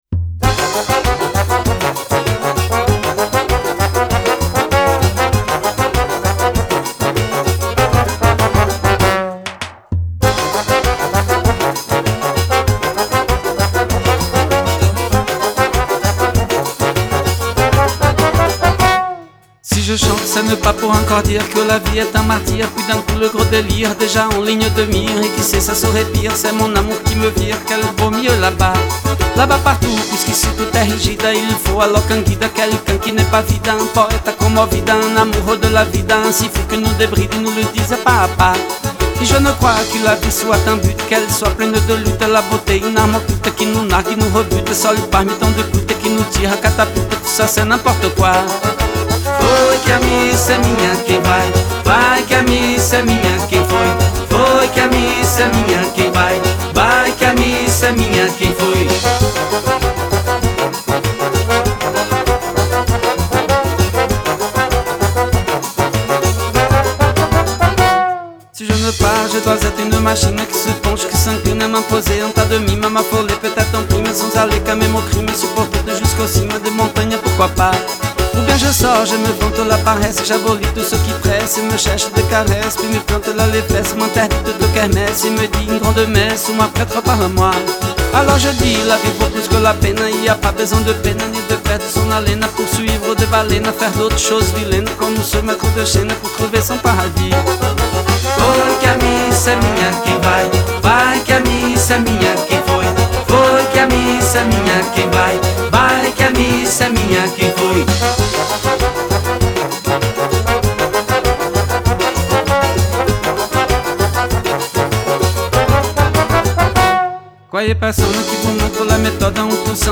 Genre : Forró